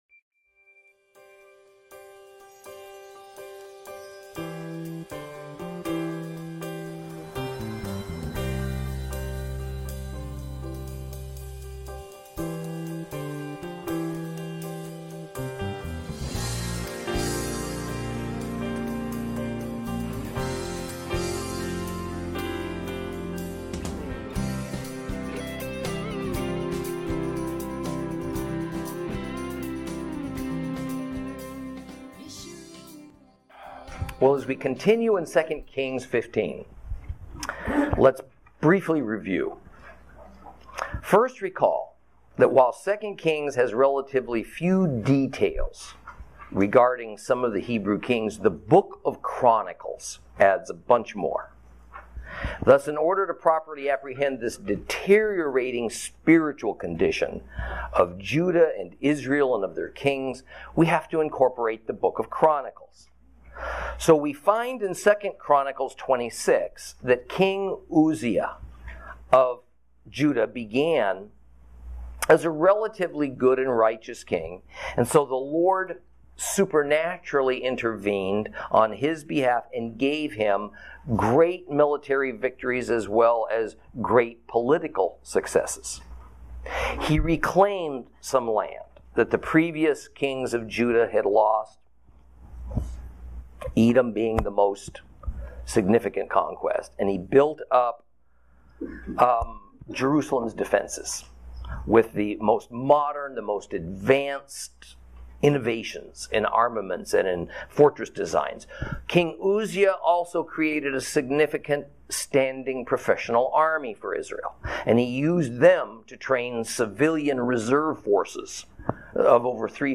Lesson 22 Ch15 - Torah Class